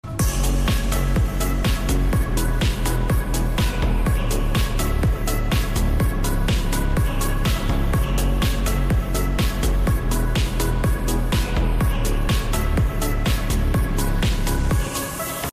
Part 68｜YEAHBOX speaker Sonic Boom sound effects free download
Three-way subwoofer